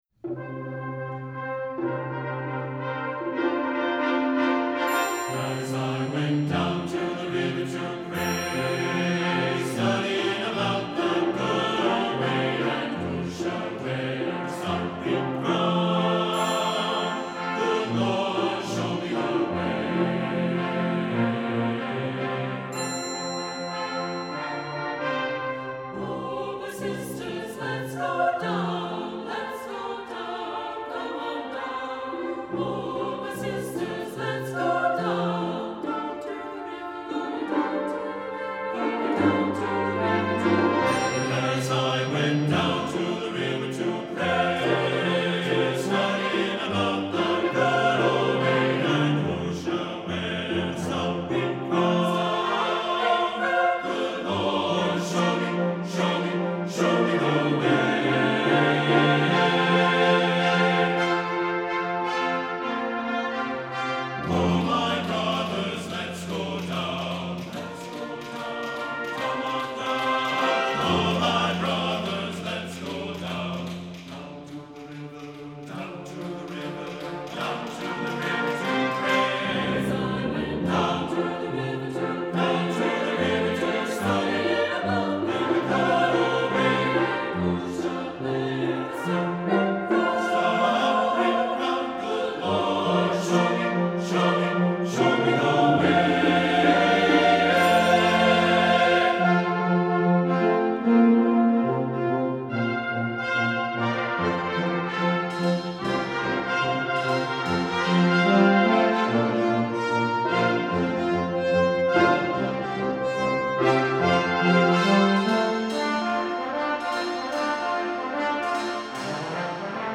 for SATB Chorus and Piano (2015)